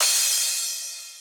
Cymbals Crash 10.ogg